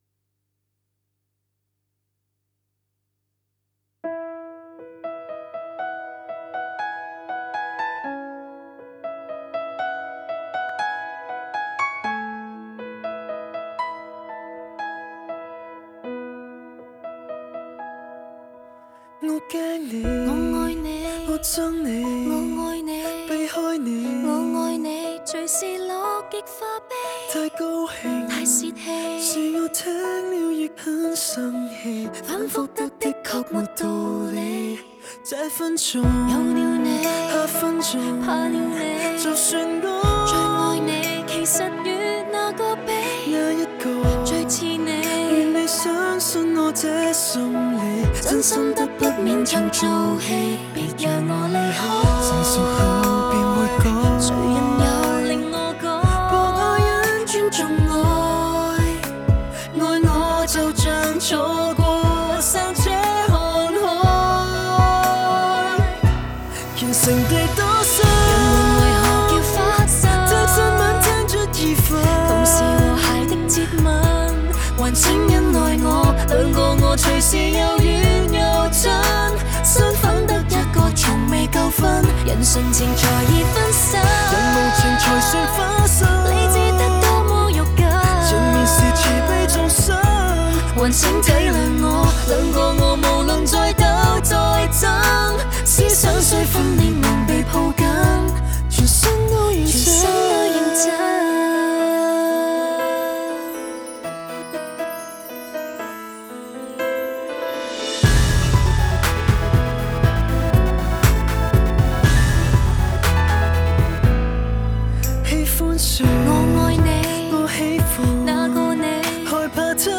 (合唱版)